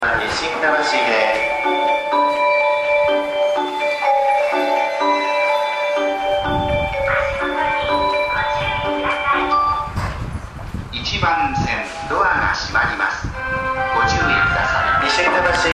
スピー カーはユニペックス小丸型が設置されており音質は比較的良いと思いますね。
発車メロディー余韻切りです。
立ち番の放送と非常にかぶりやすいです。